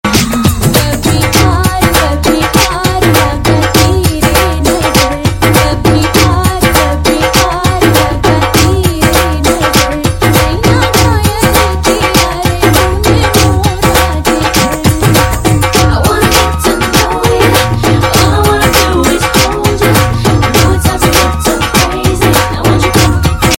Navratri Ringtones